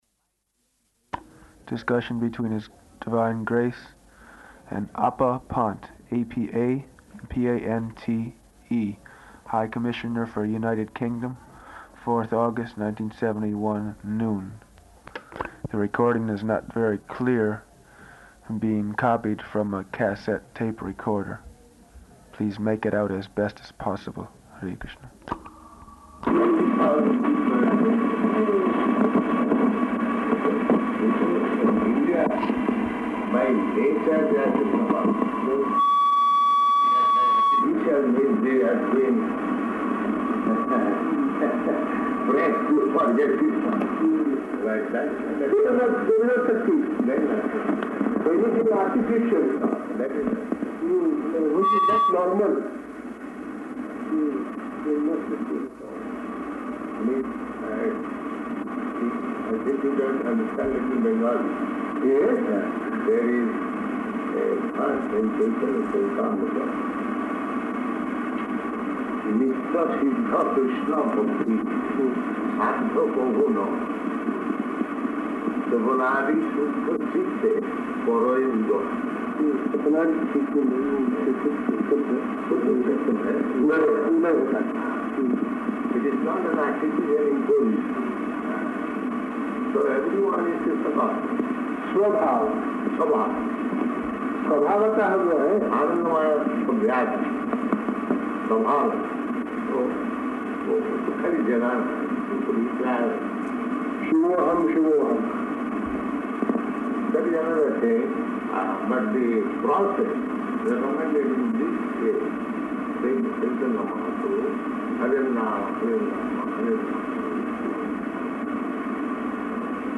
Type: Conversation
Location: London
The recording is not very clear, being copied from a cassette tape recorder.